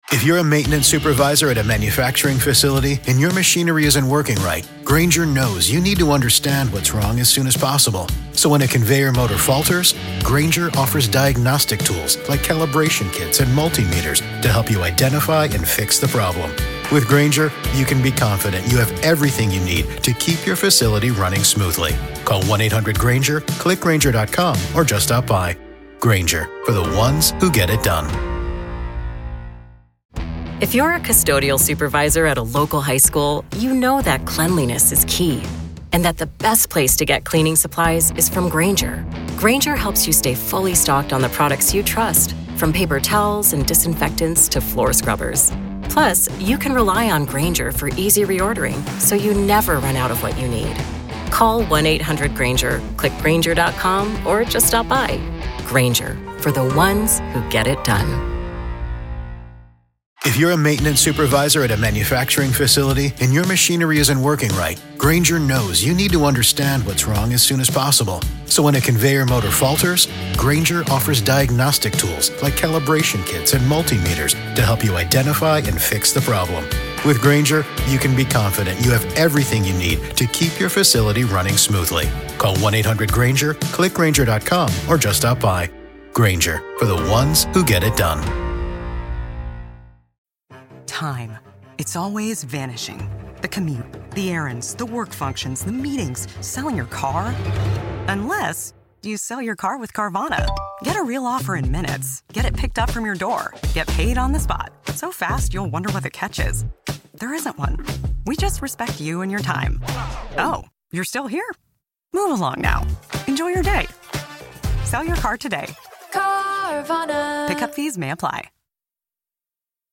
🔔 Subscribe for more deep-dive true crime interviews and expert breakdowns.